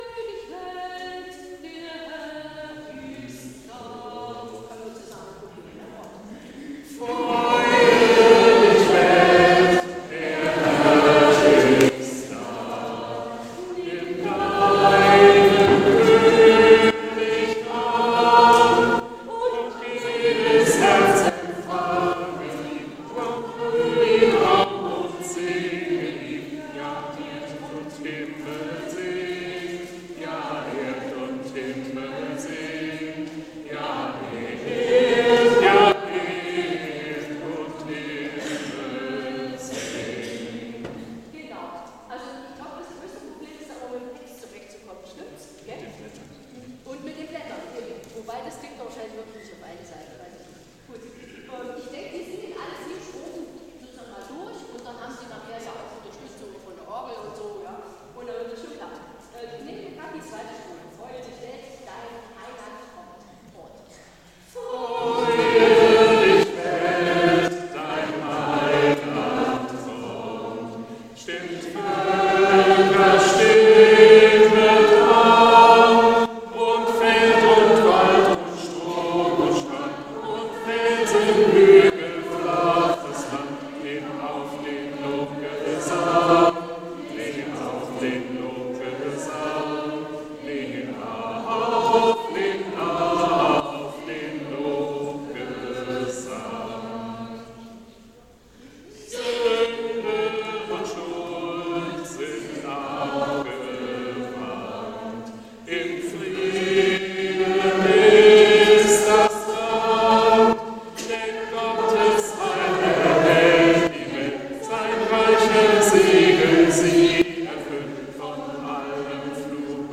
Gottesdienst vom 30.11.2025 als Audio-Podcast Liebe Gemeinde, herzliche Einladung zum Gottesdienst am 1. Advent 2025 in der Martinskirche Nierstein als Audio-Podcast.